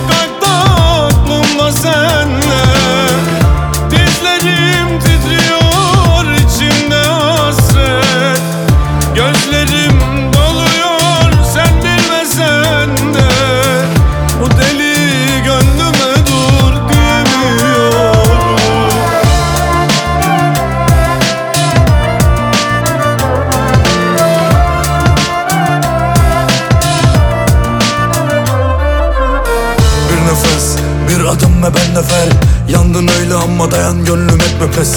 Turkish Pop Pop
Жанр: Поп музыка